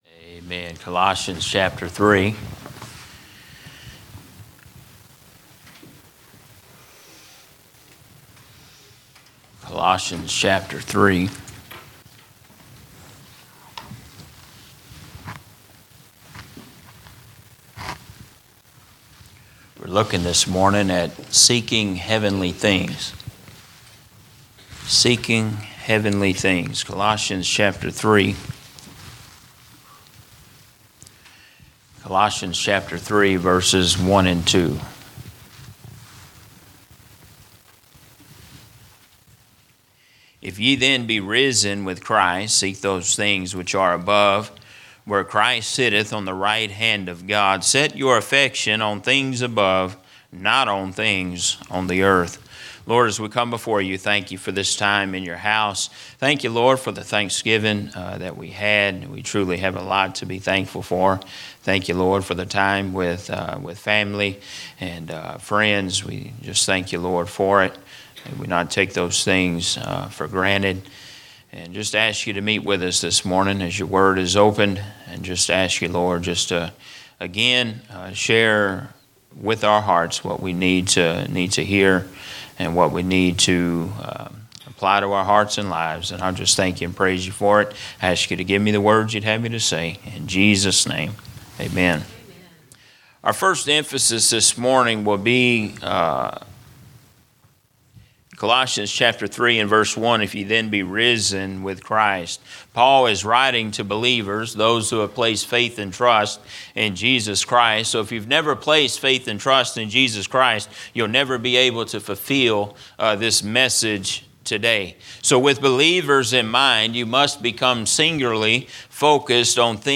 Sermons - Emmanuel Baptist Church
From Series: "General Preaching"